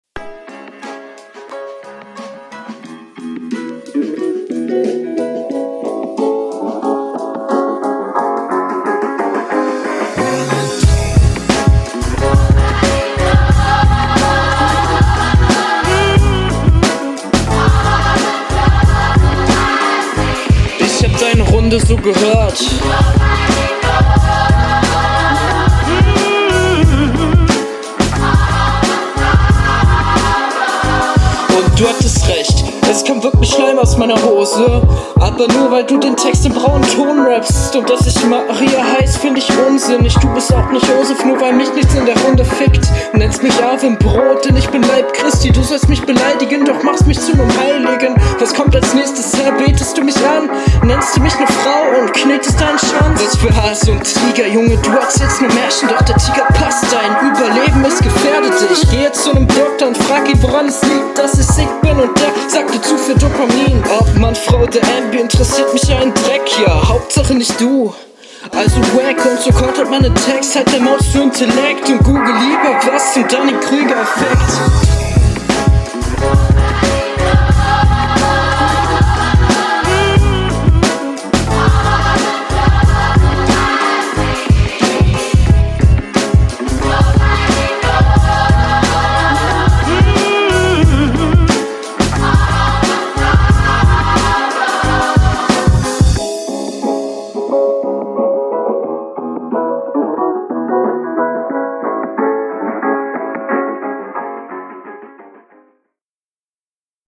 Flow stabil, Stimmeinsatz besser fürs battlen.
Du flowst besser auf dem Beat als ich das erwartet hätte.